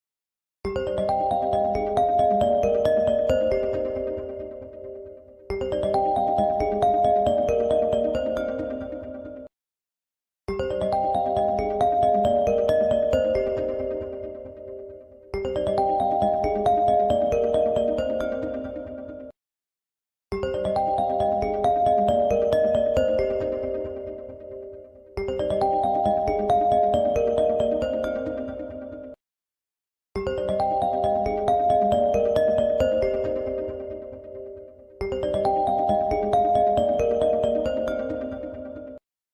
Genre: Nada dering asli